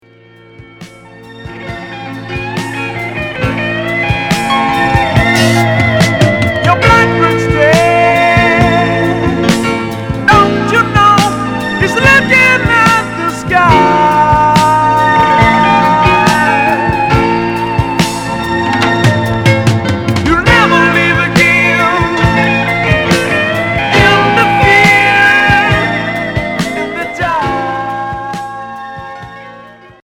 Hard progressif Unique 45t